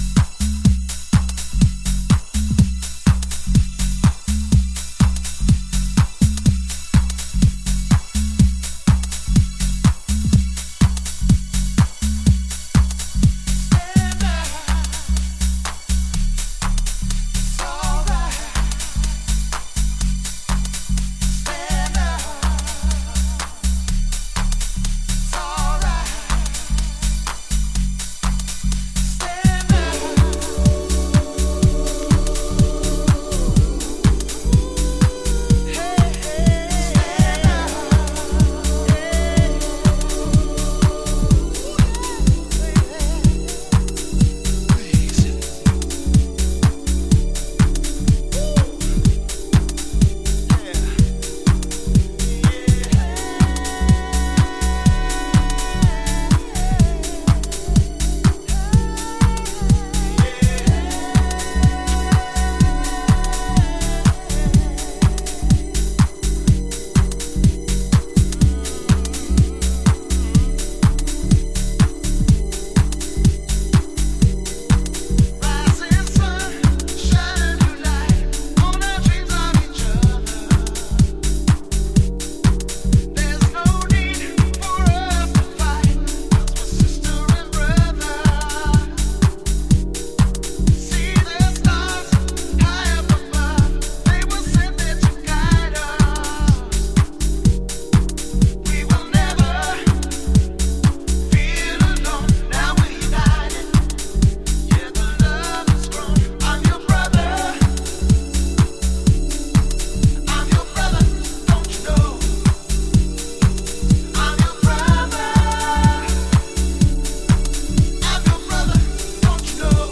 House / Techno
都会的な哀愁が滲み出た、当時のハウスの本気度が伺える名盤。